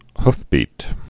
(hfbēt, hf-)